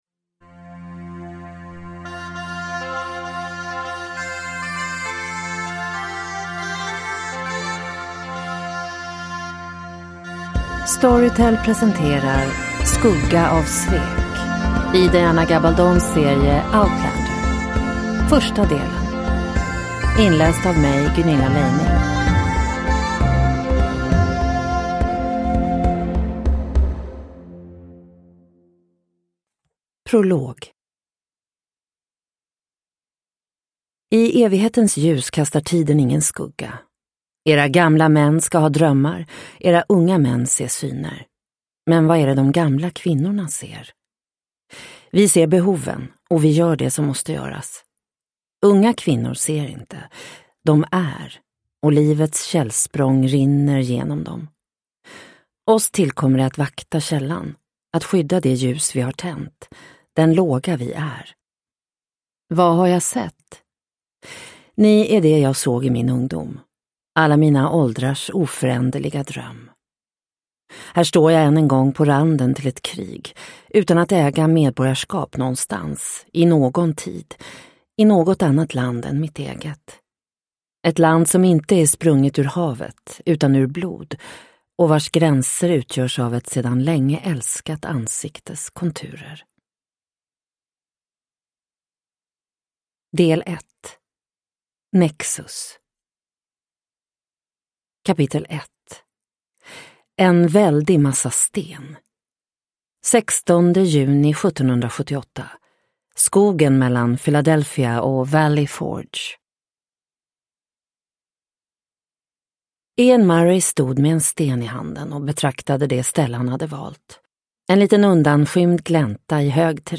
Skugga av svek - del 1 – Ljudbok – Laddas ner